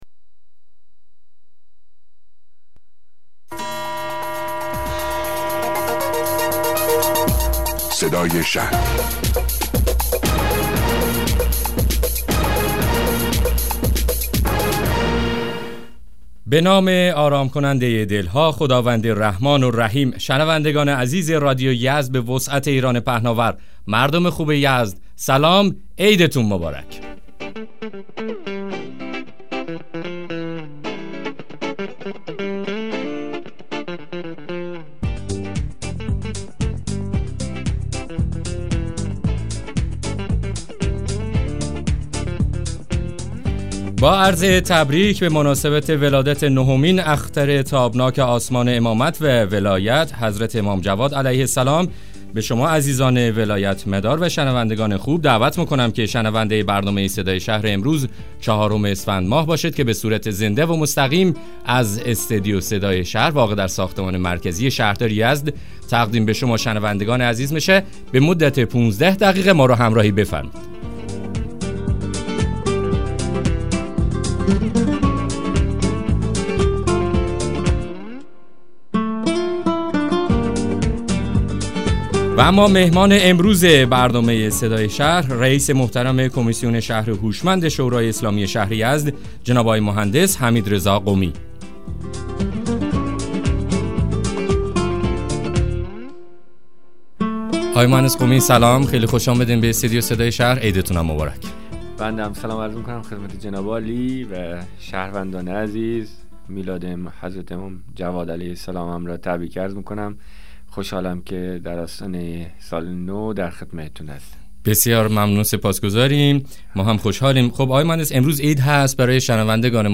مصاحبه رادیویی برنامه صدای شهر با مهندس حمیدرضا قمی رئیس کمیسیون شهر هوشمند شورای اسلامی شهر یزد